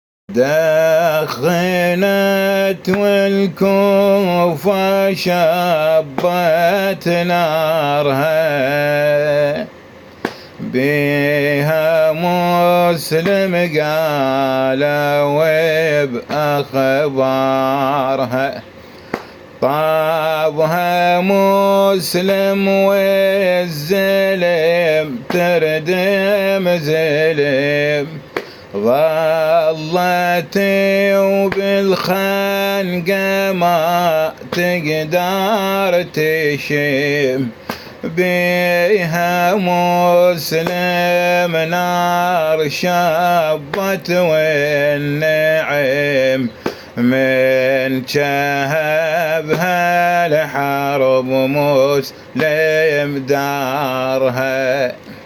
موشح نجفي في حق مسلم بن عقيل (عليه السلام)
وهذا لحن القصيدة / قصائد حسينية مكتوبة مع اللحن